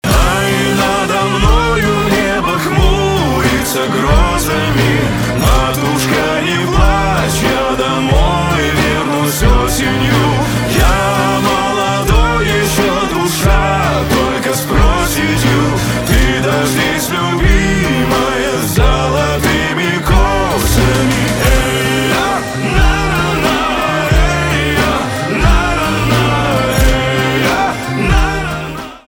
саундтрек
гитара